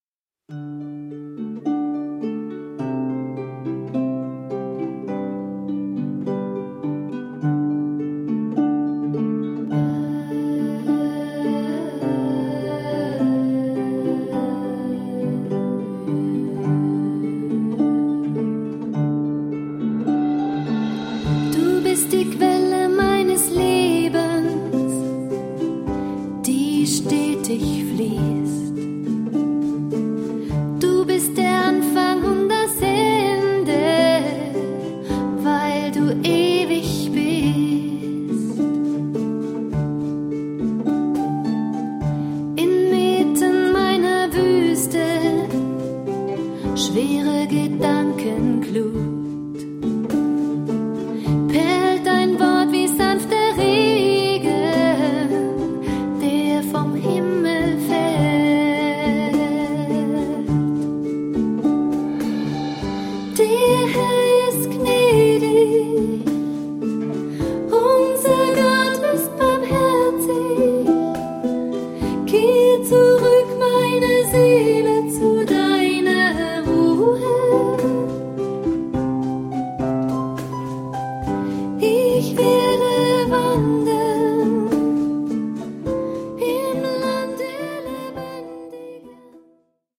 Gesang und Harfe stehen im Mittelpunkt
percussion
Sopran- und Tenorsaxophon, Klarinette
Tin Whistle, Cello
Lobpreis